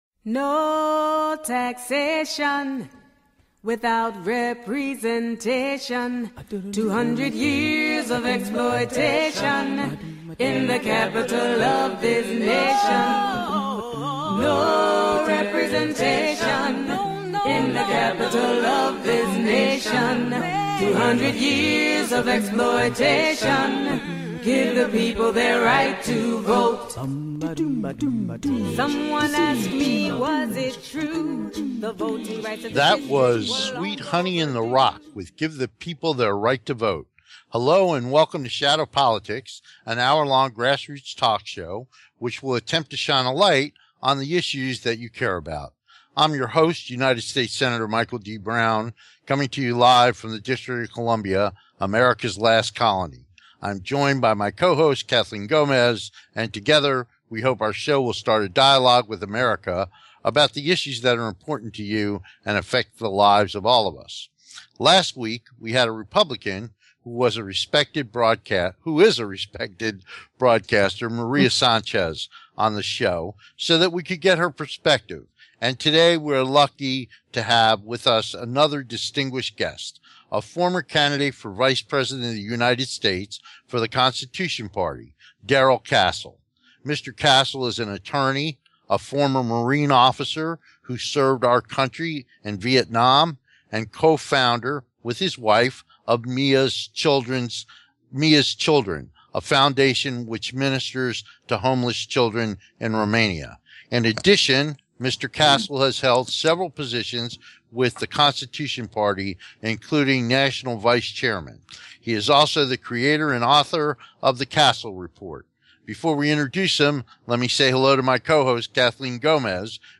The show is not only informative but intertaining, and they both convey their opinions with humor, wit and a strong rapport.
Shadow Politics is a grass roots talk show giving a voice to the voiceless.